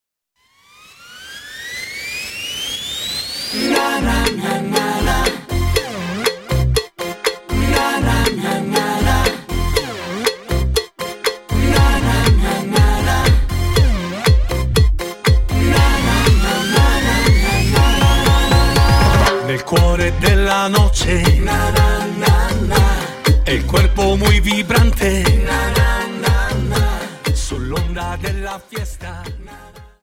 Cha Cha 31 Song